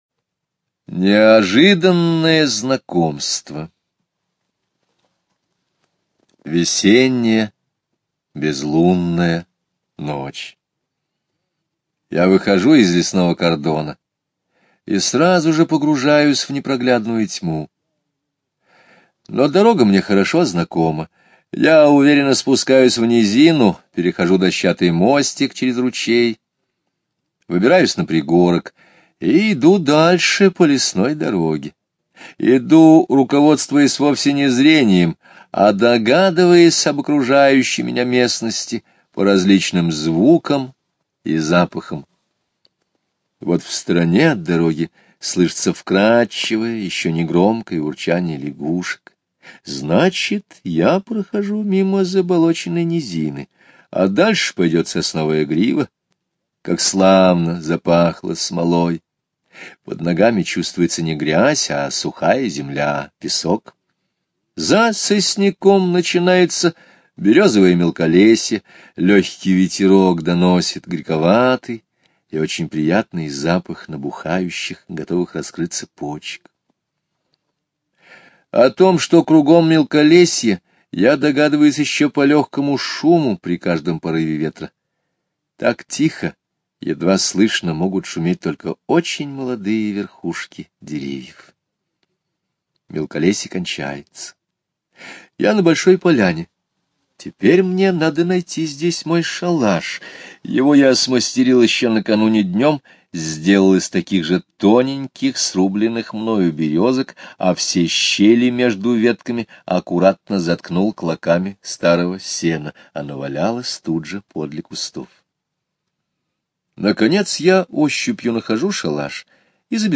Неожиданное знакомство - Скребицкий - слушать рассказ онлайн